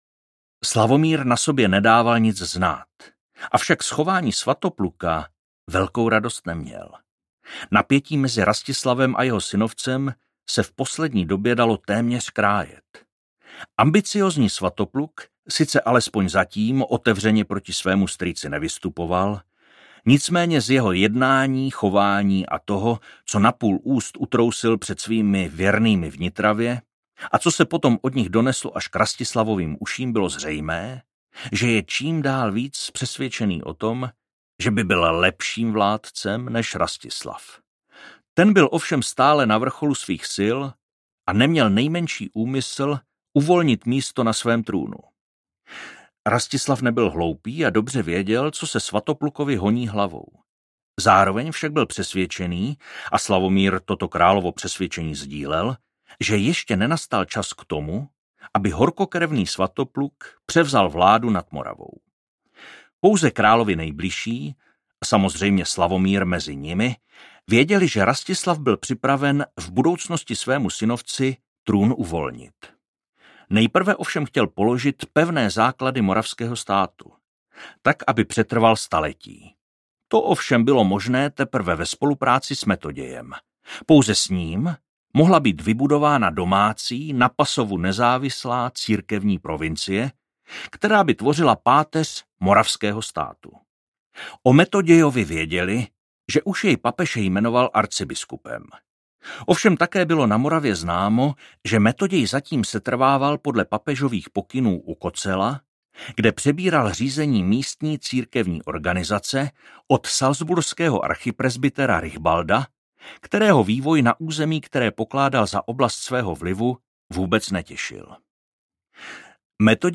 Případ zrazeného krále audiokniha
Ukázka z knihy